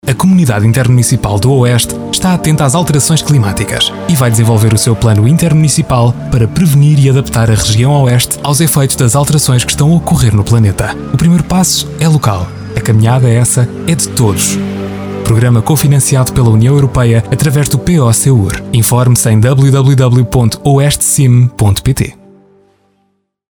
SpotRadioPIAAC.mp3